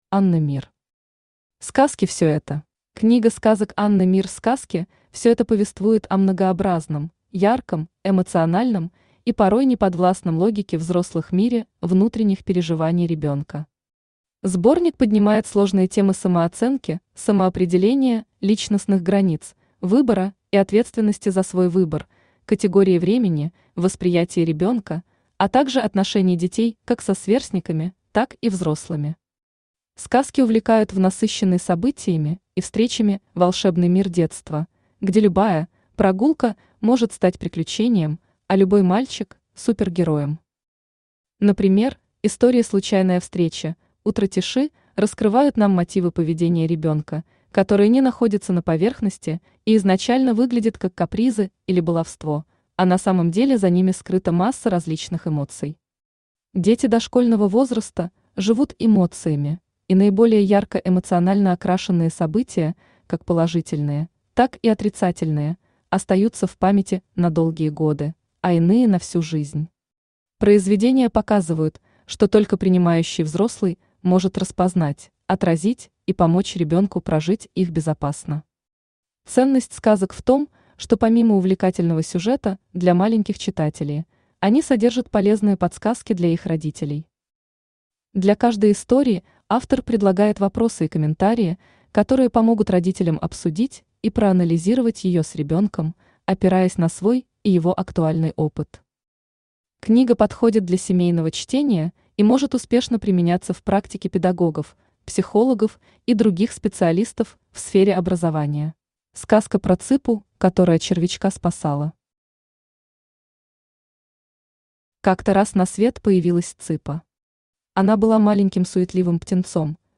Аудиокнига Сказки всё это | Библиотека аудиокниг
Aудиокнига Сказки всё это Автор Анна Мир Читает аудиокнигу Авточтец ЛитРес.